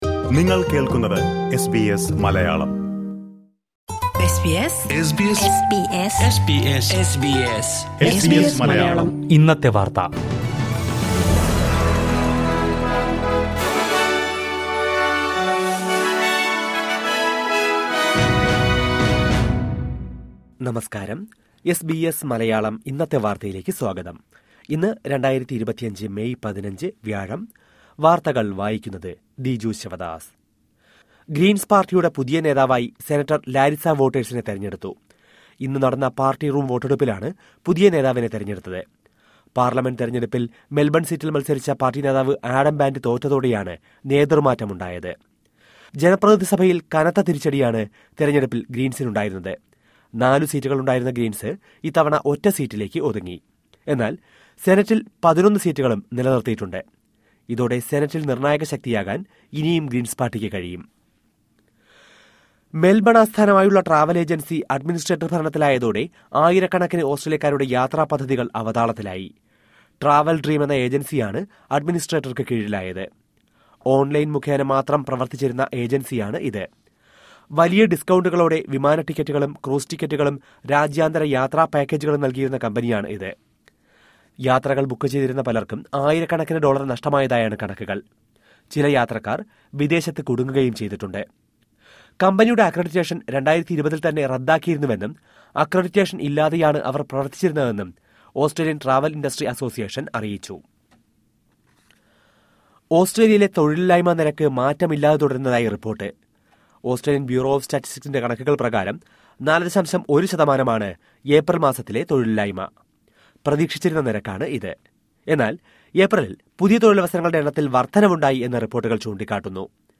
2025 മേയ് 15ലെ ഓസ്‌ട്രേലിയയിലെ ഏറ്റവും പ്രധാന വാര്‍ത്തകള്‍ കേള്‍ക്കാം...